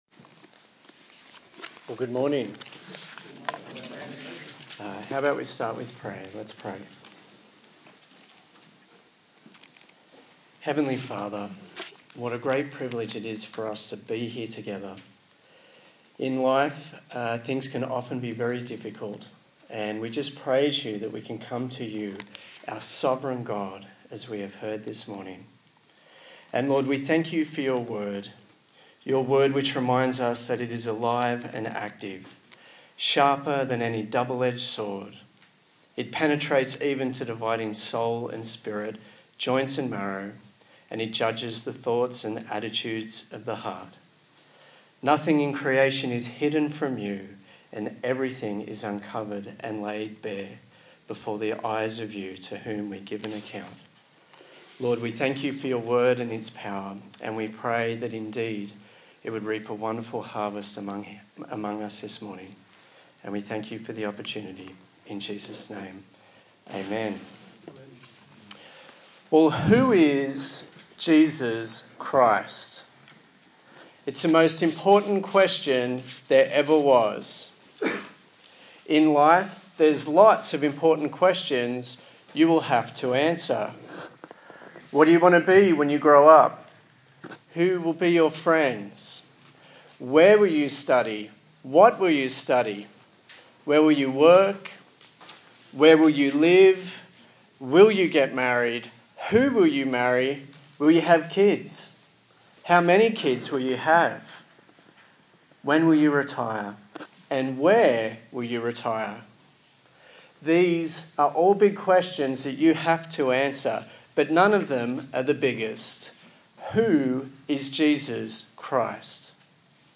Colossians Passage: Colossians 1:15-23 Service Type: Sunday Morning